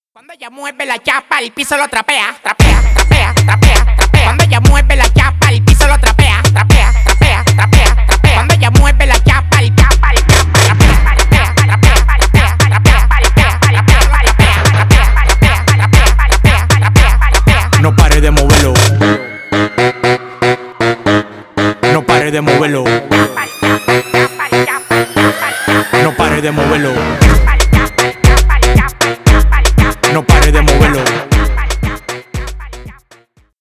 Рэп и Хип Хоп
весёлые